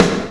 ENX SNR 5.wav